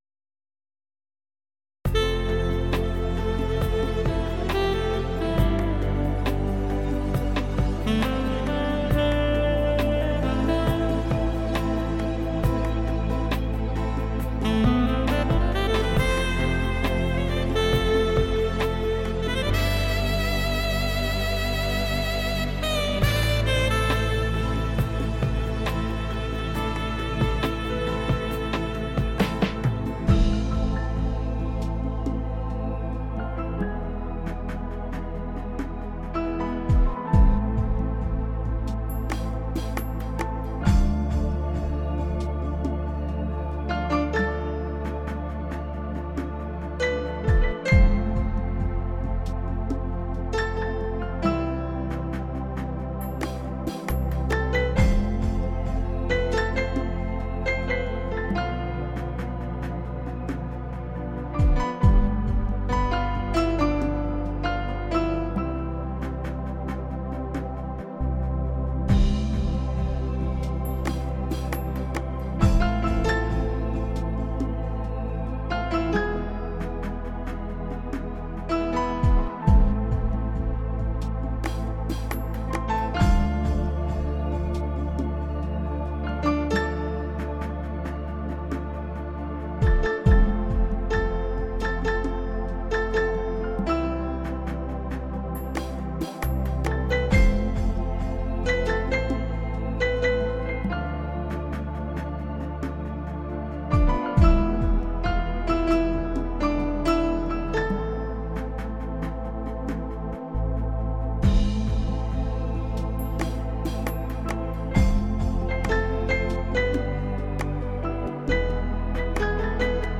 Chord Arrangement & Keyboard